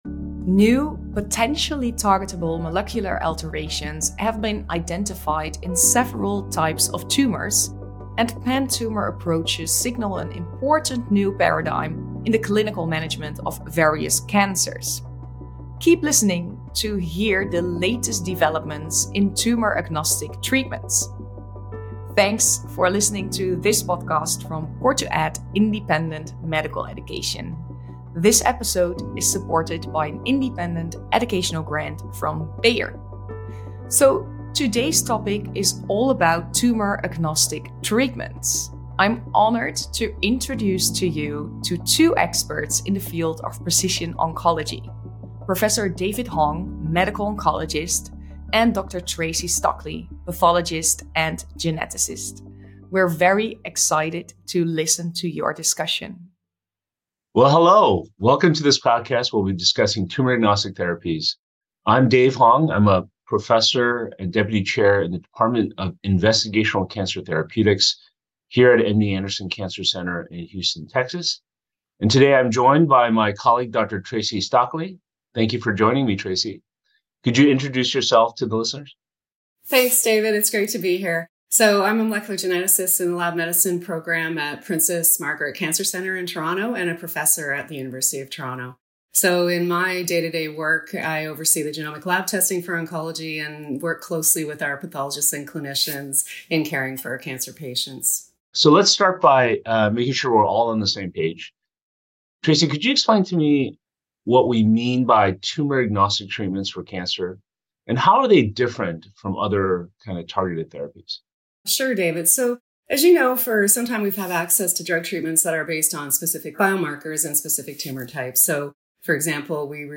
As the conversation unfolds, the precision oncology experts explore the issues and challenges associated with developing tumour agnostic therapies and discuss how these treatments differ from others.